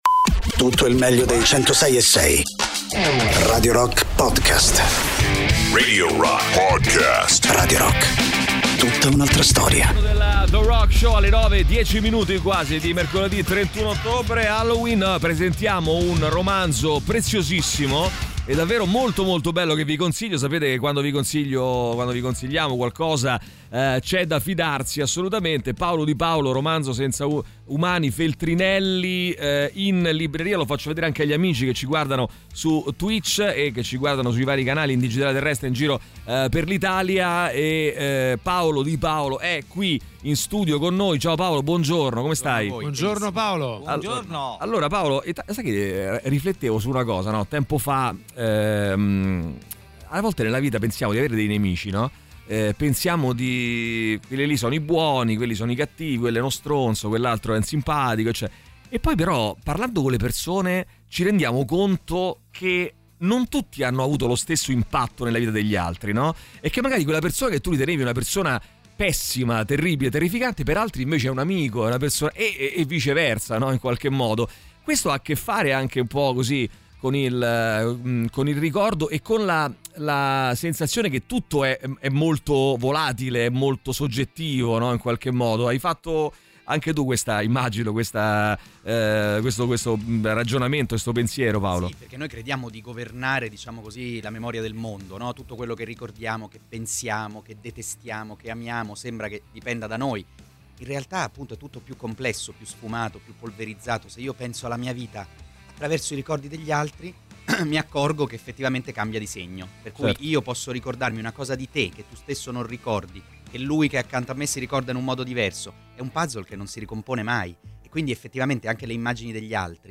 Paolo Di Paolo, autore del libro Romanzo senza umani, ospite ai microfoni di Radio Rock durante il THE ROCK SHOW.